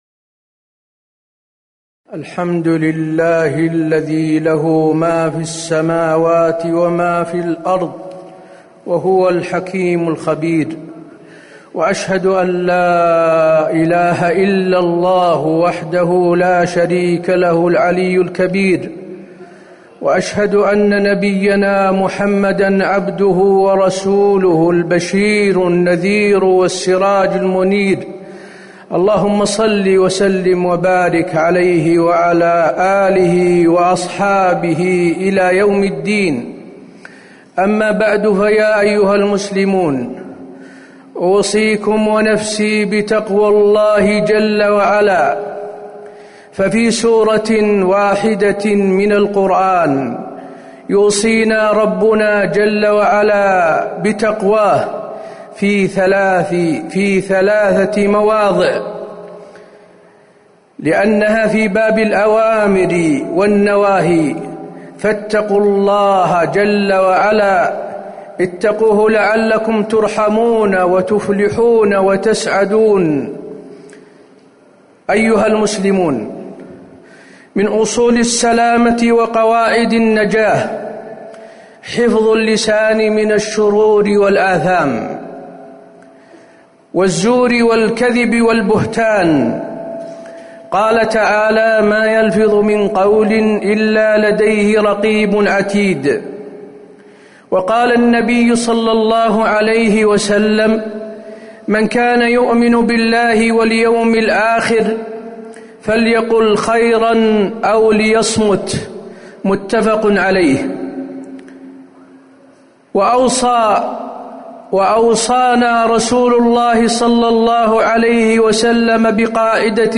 تاريخ النشر ١٨ ربيع الأول ١٤٤١ هـ المكان: المسجد النبوي الشيخ: فضيلة الشيخ د. حسين بن عبدالعزيز آل الشيخ فضيلة الشيخ د. حسين بن عبدالعزيز آل الشيخ الغيبة